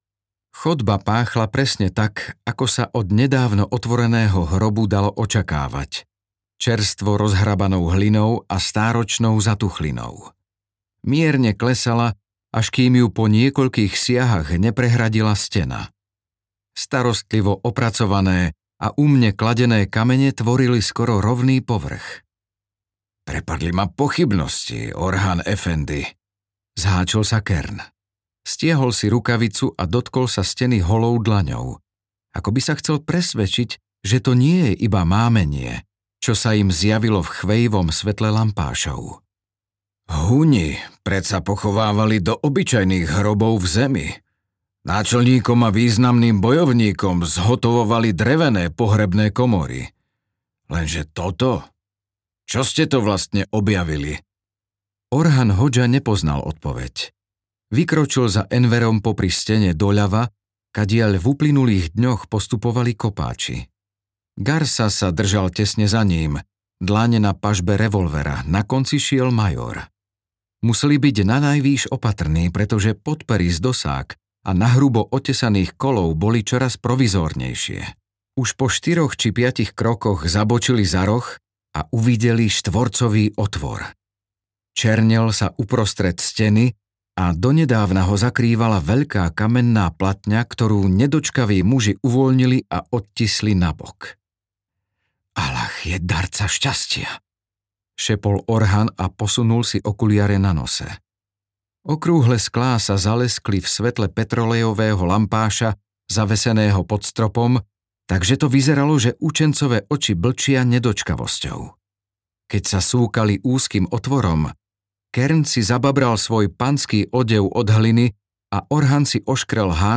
Zlatá truhla audiokniha
Ukázka z knihy